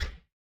Minecraft Version Minecraft Version latest Latest Release | Latest Snapshot latest / assets / minecraft / sounds / block / crafter / fail.ogg Compare With Compare With Latest Release | Latest Snapshot